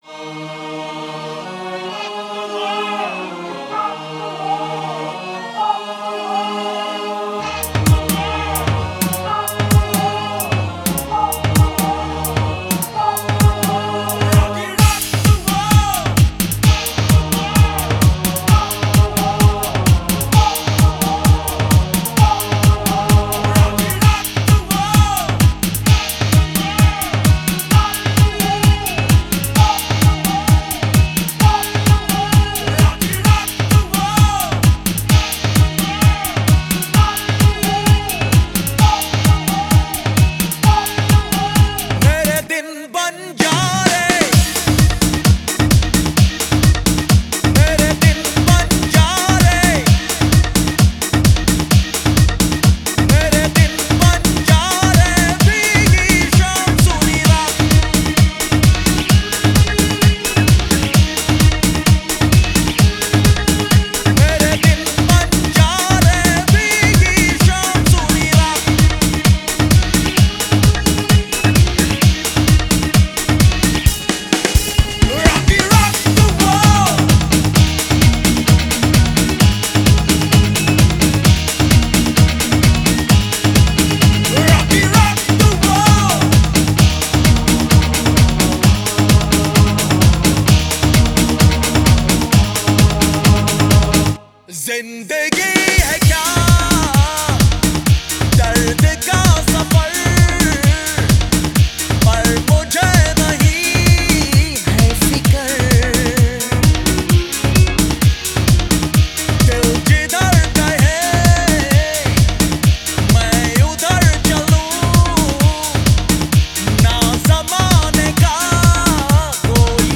Bollywood MP3 Songs